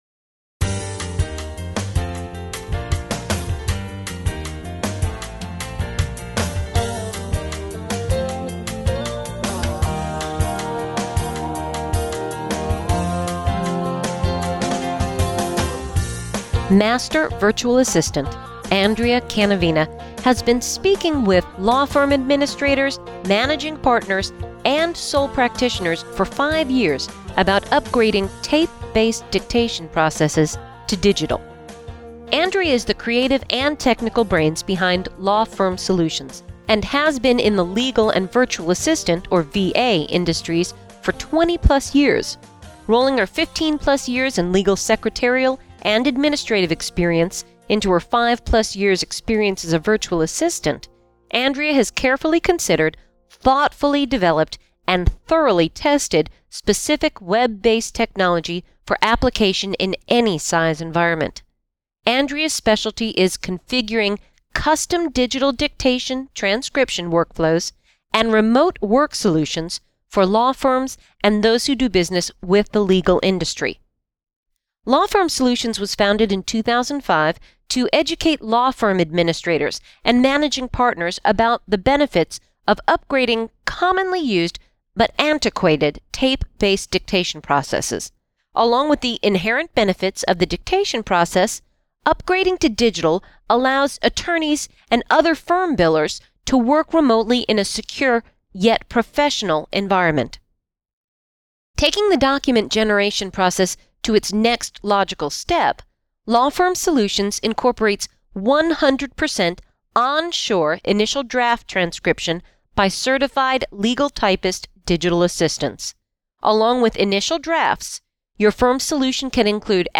professionally mastered audio business card